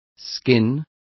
Also find out how despellejábamos is pronounced correctly.